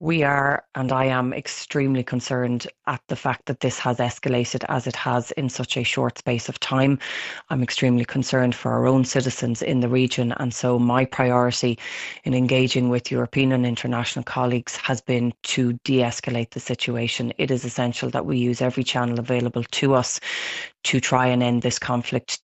Minister McEntee says she’ll update the forum on a virtual meeting with other EU foreign ministers last night, during which they called for a de-escalation of the conflict: